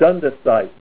Help on Name Pronunciation: Name Pronunciation: Dundasite + Pronunciation